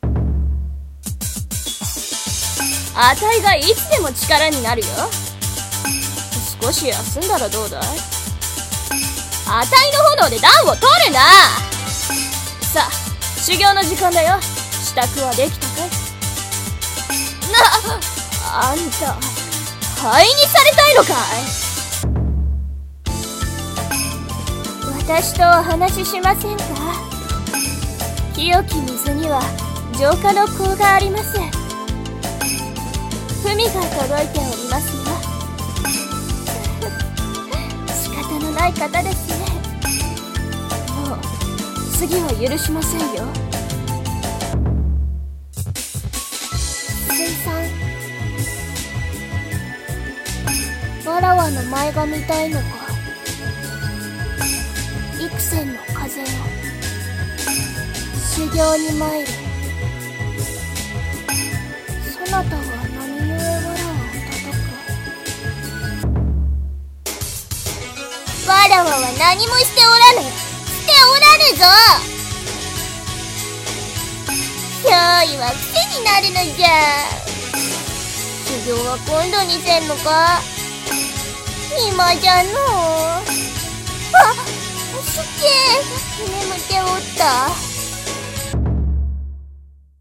【演じ分け台本】
女声/少年声用